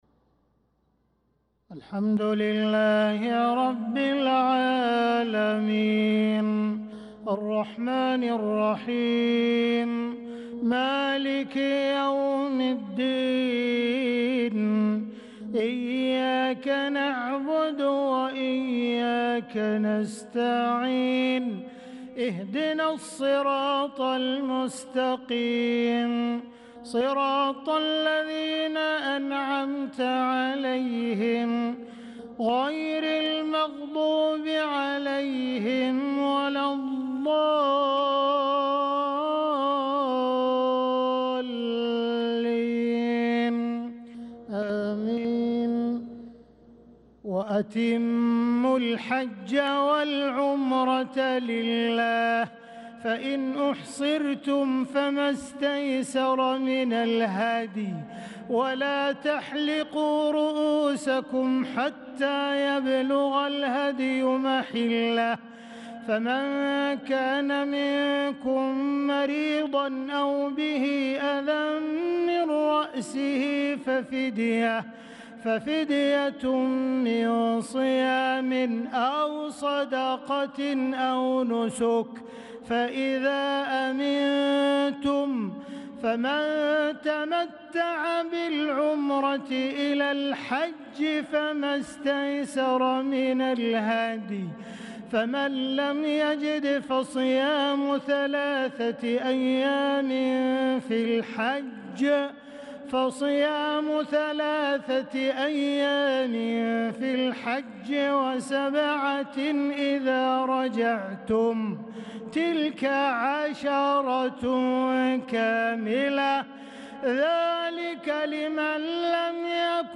صلاة العشاء للقارئ عبدالرحمن السديس 13 ذو القعدة 1445 هـ
تِلَاوَات الْحَرَمَيْن .